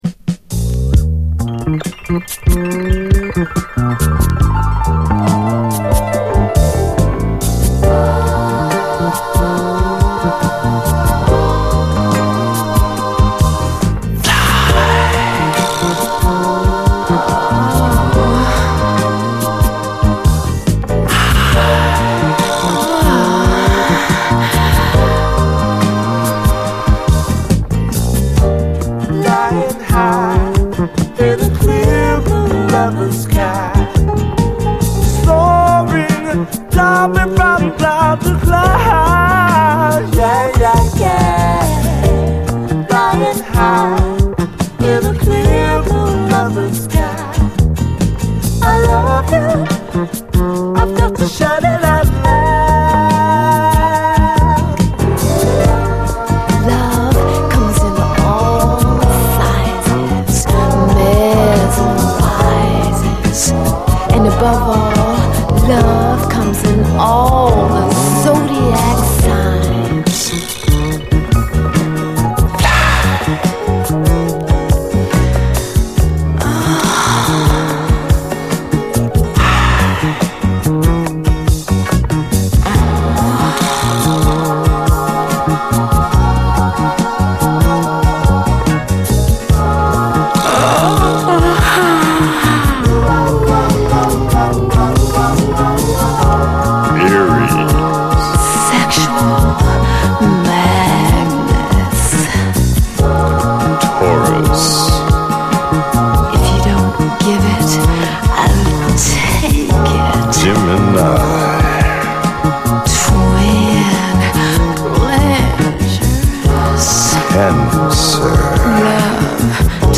ブラスがないシンプルなバックなのでコーラスの美しさがさらに際立って聞こえます！
後半はダブに接続。